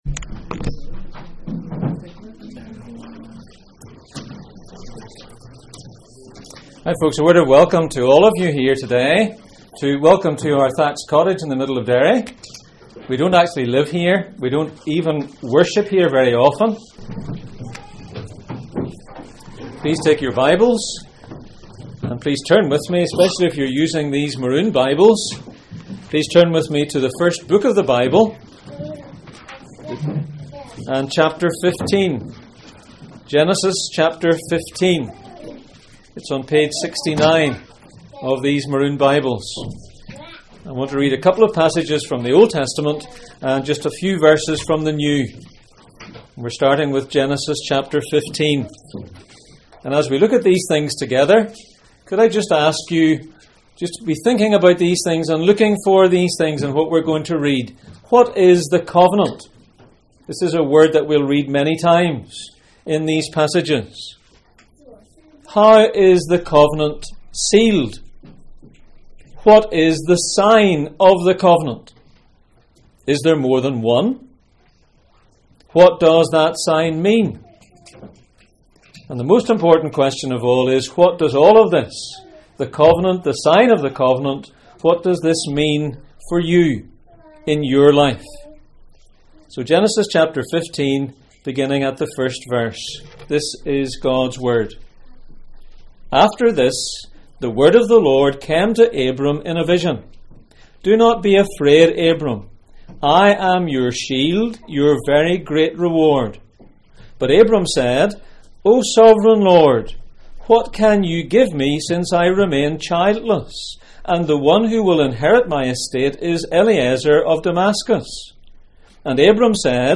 Baptism Passage: Genesis 3:15, Genesis 15:1-21, Genesis 17:1-27, Genesis 21:4, Acts 2:36-39, Jeremiah 4:4, Romans 4:11 Service Type: Sunday Morning